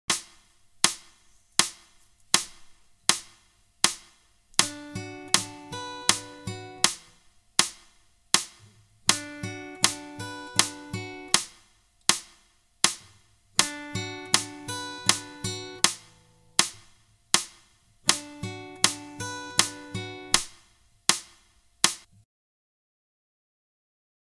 Finger Pick Hand
Half Speed mp3
Repeats 4X
7th position B minor triad.